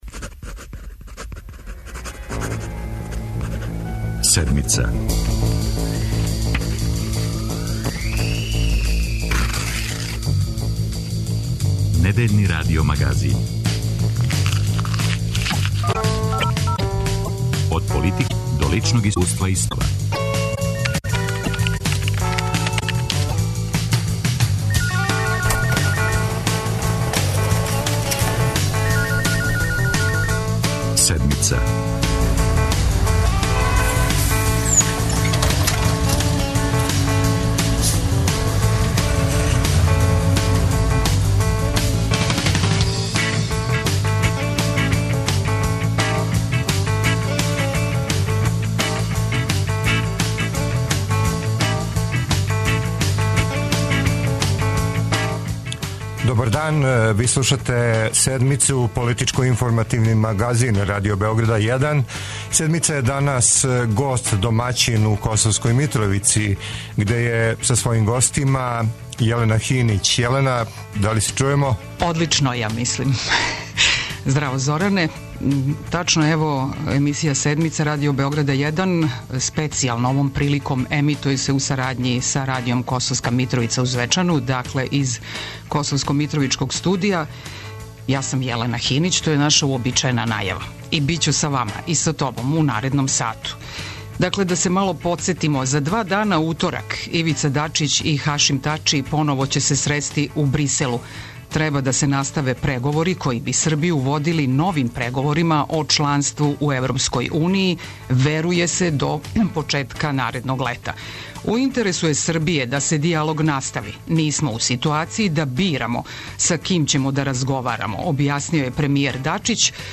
Радио Београд 1 и Радио Косовска Митровица реализују заједничку емисију из студија локалног радија у Косовској Митровици.
Представници косовских Срба, власти у Београду и аналитичари из Приштине коментаришу хашке пресуде, говоре о судбини преговора Београд - Приштина, интегрисаним прелазима и перспективама живота на Косову и Метохији.